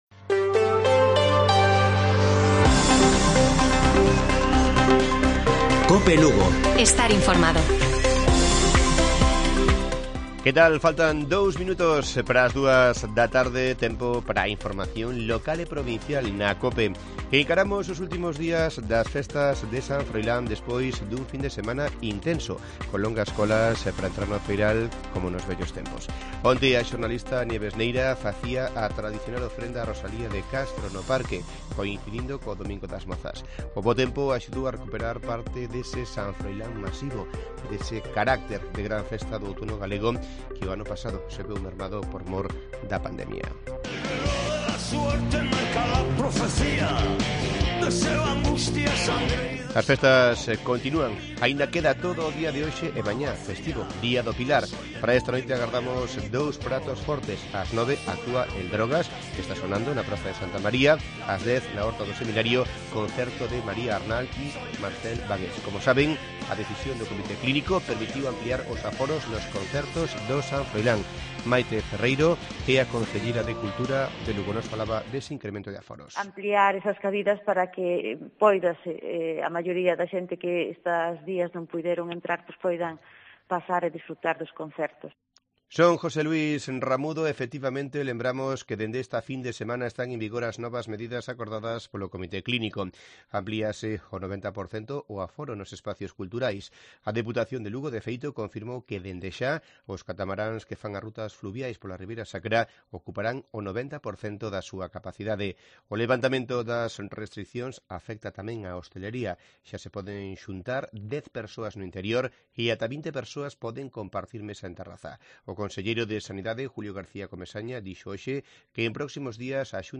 Informativo Mediodía de Cope Lugo. 11 de octubre. 13:50 horas